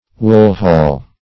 Wool-hall \Wool"-hall`\, n. A trade market in the woolen districts.